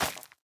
Minecraft Version Minecraft Version 25w18a Latest Release | Latest Snapshot 25w18a / assets / minecraft / sounds / item / plant / netherwart1.ogg Compare With Compare With Latest Release | Latest Snapshot
netherwart1.ogg